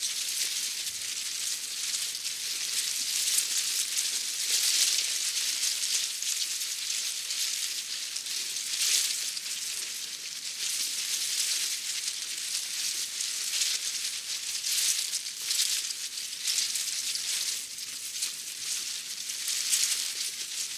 windbush_1.ogg